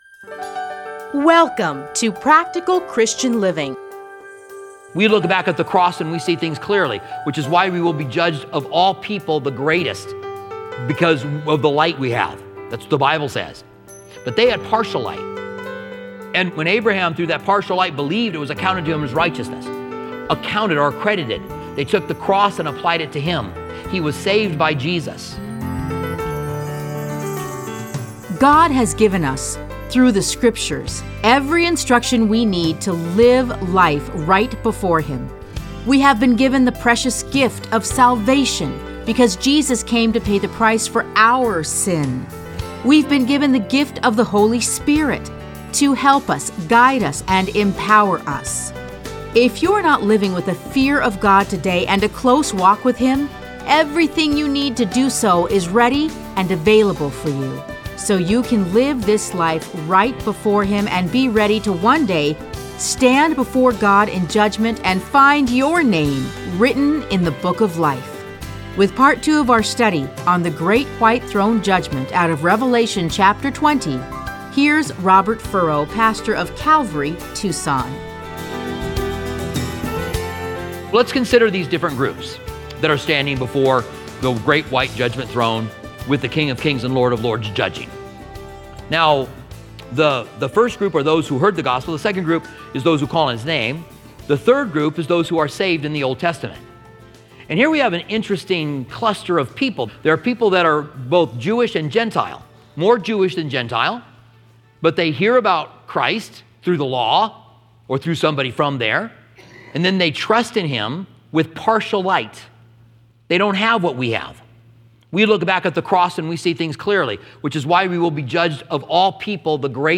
Listen to a teaching from Revelation 20:11-15.